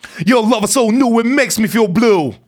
RAPHRASE15.wav